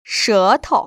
[shé‧tou] 서토우  ▶